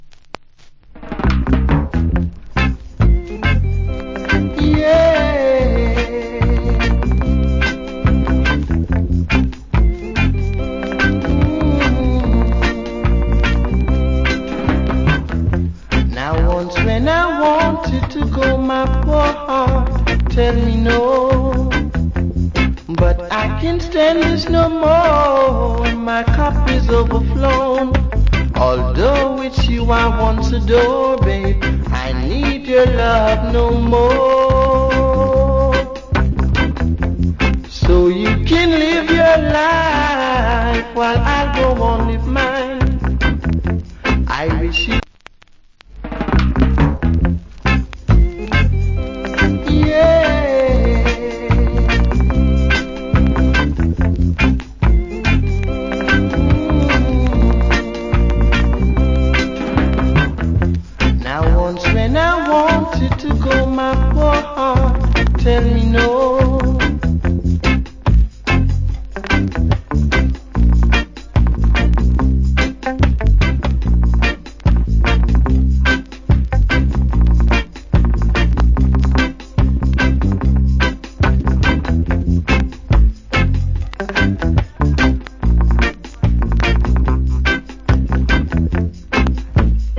Great Reggae Vocal.